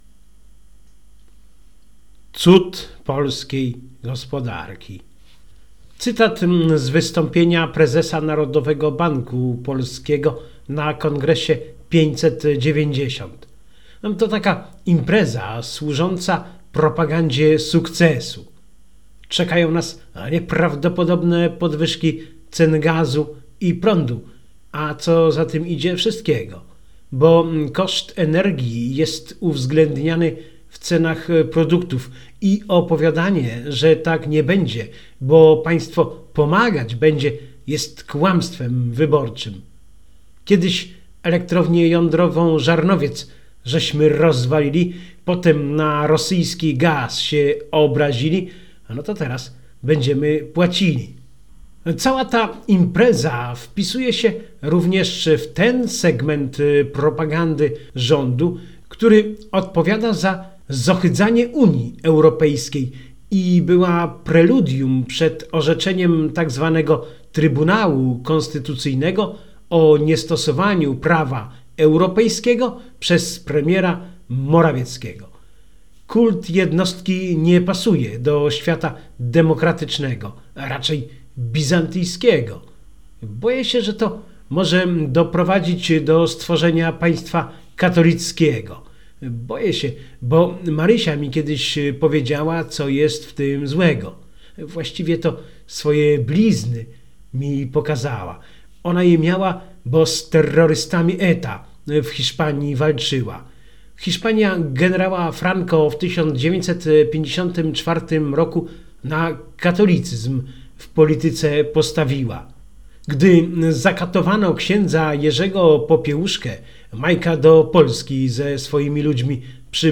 Cytat z wystąpienia prezesa NBP na Kongresie 590.